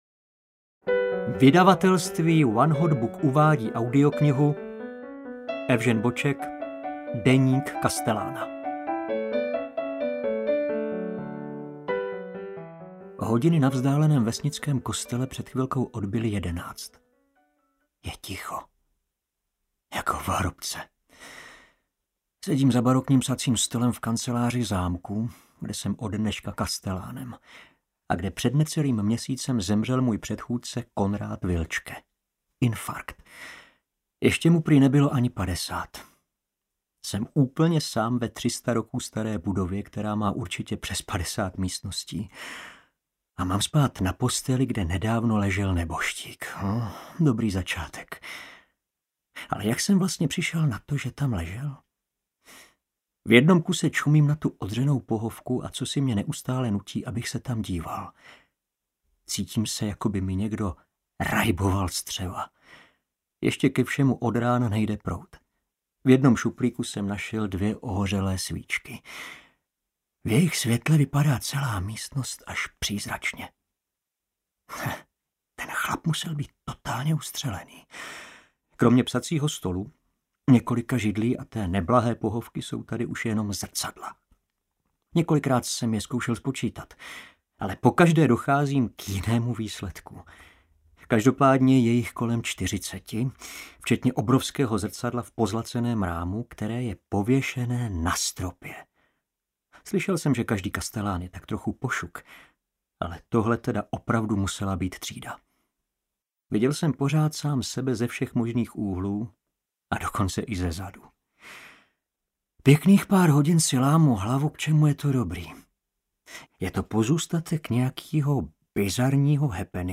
Deník kastelána audiokniha
Ukázka z knihy
• InterpretJaroslav Plesl